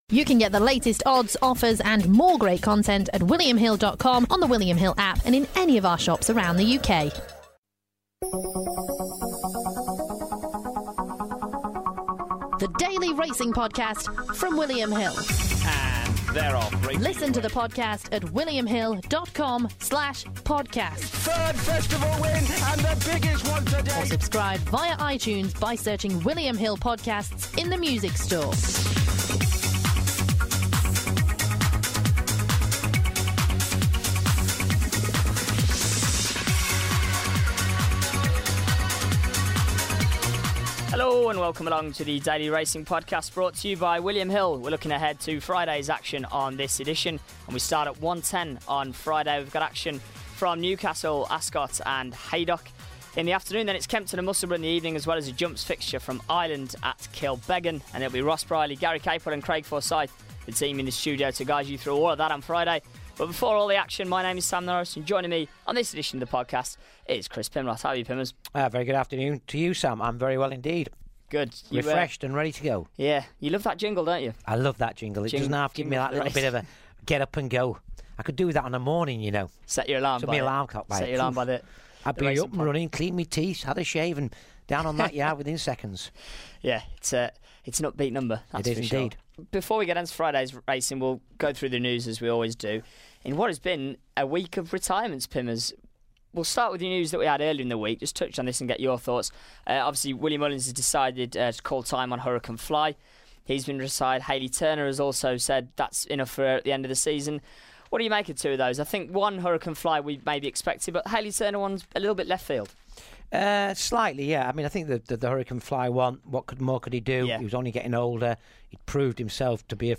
in the William Hill studio